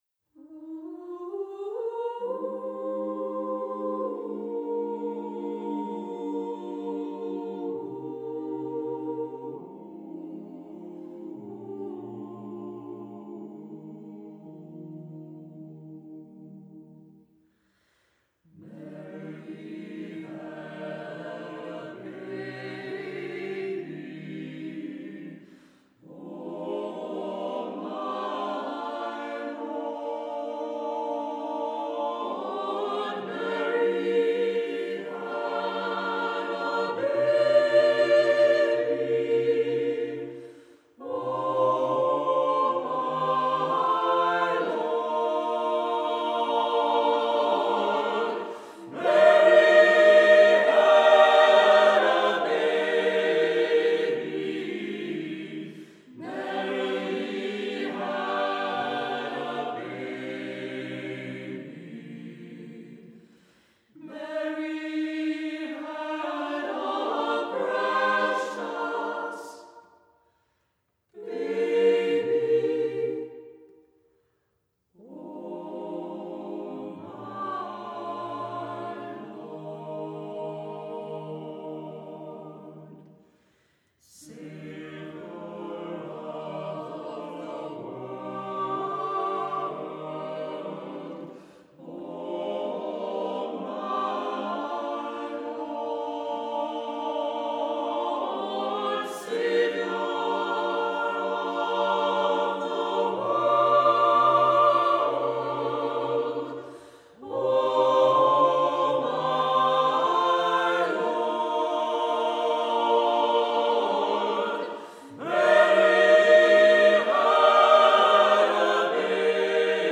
Accompaniment:      A Cappella, Piano reduction
Music Category:      Christian